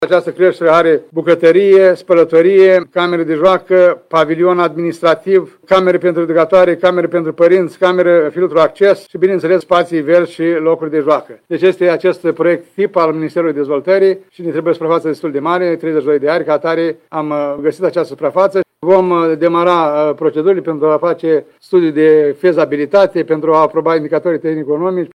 Primarul ION LUNGU a declarat astăzi că viitoarea creșă va avea 10 grupe de copii.